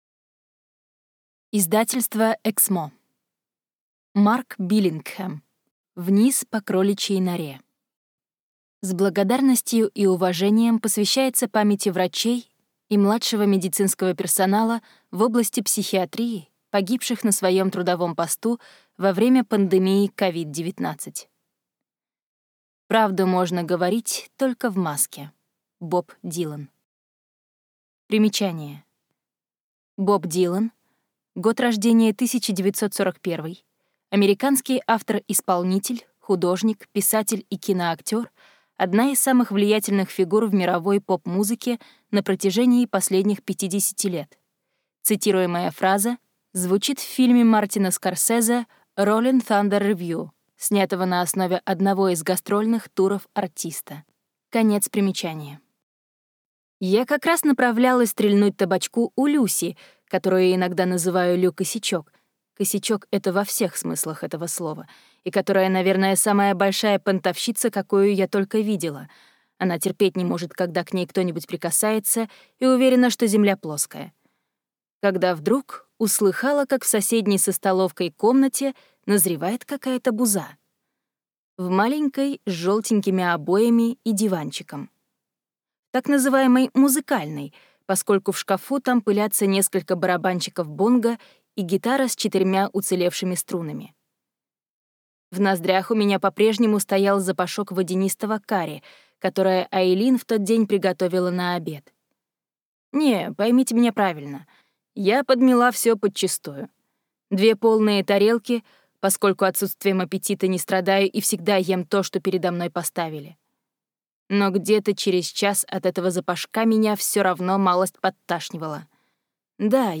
Аудиокнига Вниз по кроличьей норе | Библиотека аудиокниг